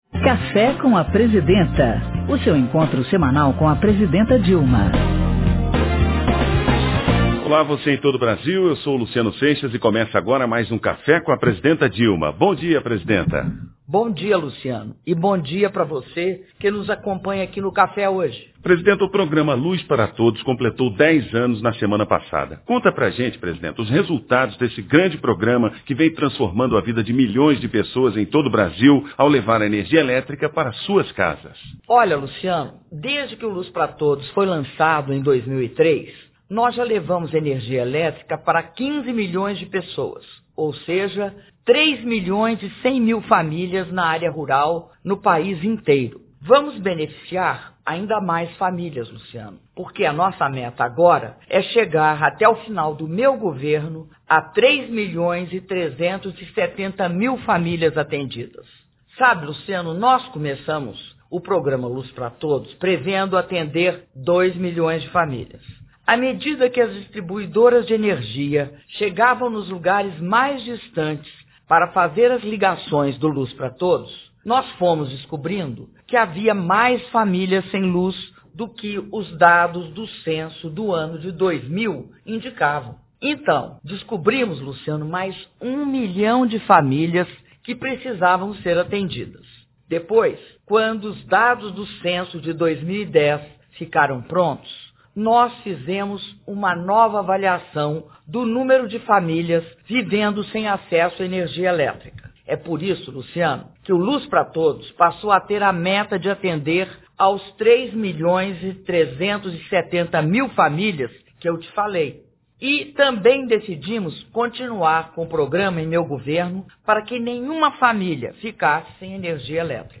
Áudio do programa de rádio "Café com a Presidenta", com a Presidenta da República, Dilma Rousseff (11min10s)